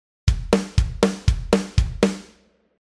一方、メタル・ハードコアパンクの2ビートは、ドッタッで刻むリズムになります。
2ビート
• 2ビート＝ハードコアパンクっぽい
また、この記事では、シンバルはすべてハイハットにしてあります。
2beat.mp3